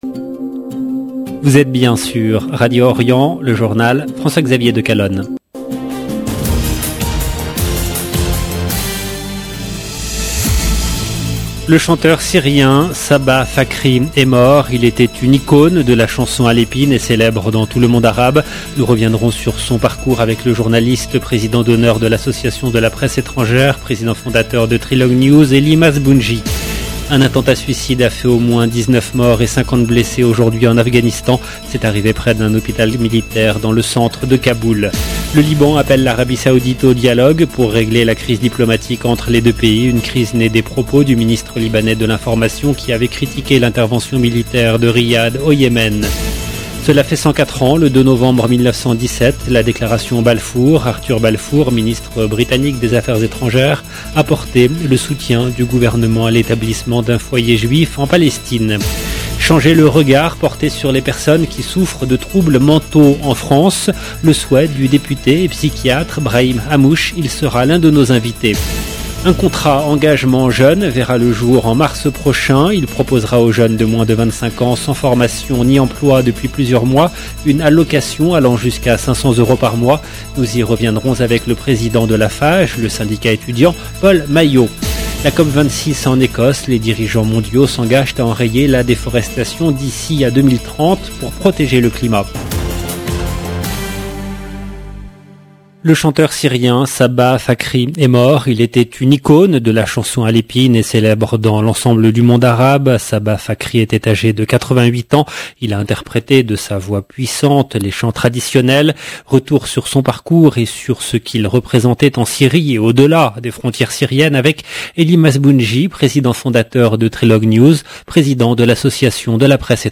EDITION DU JOURNAL DU SOIR EN LANGUE FRANCAISE DU 2/11/2021